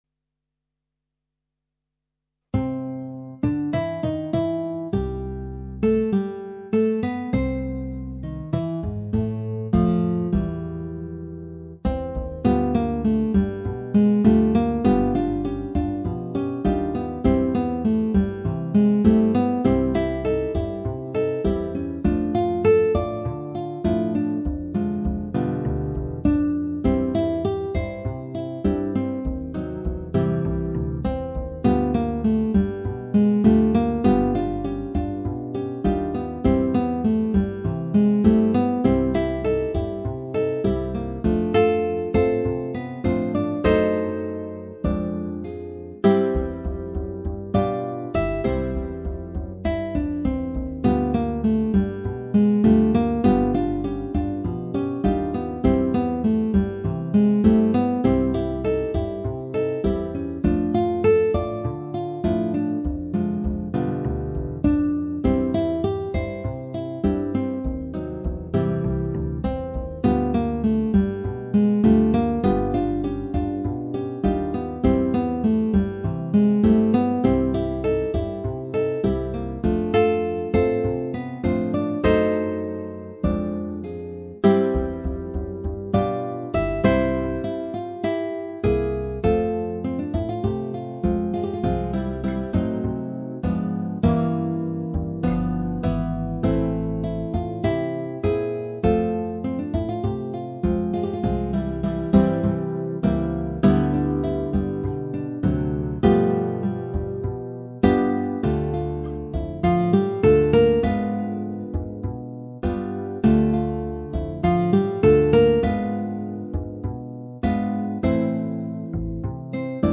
Guitar Quartet
A slow and poignant serenade with a gentle tango rhythm,